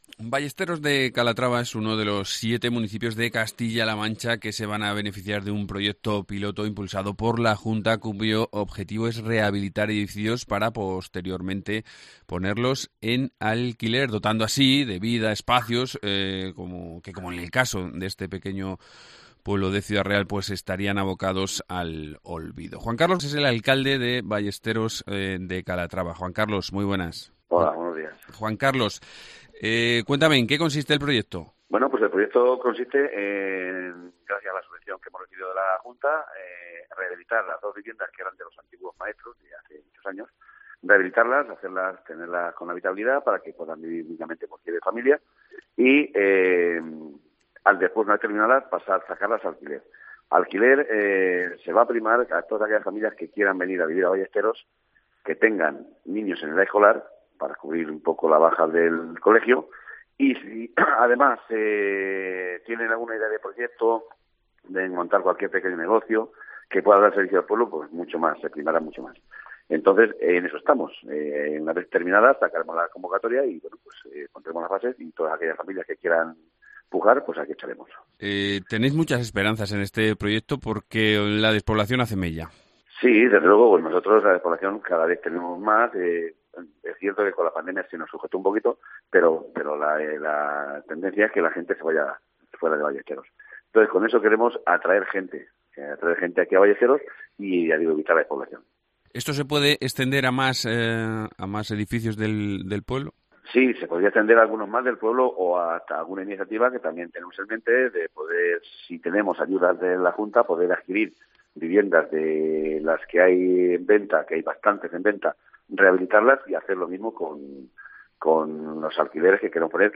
Entrevista con Juan Carlos Moraleda, alcalde de Ballesteros de Calatrava